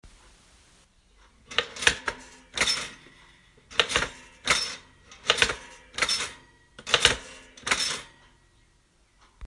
描述：烤面包机的声音
Tag: klack KLICK 金属 烤面包机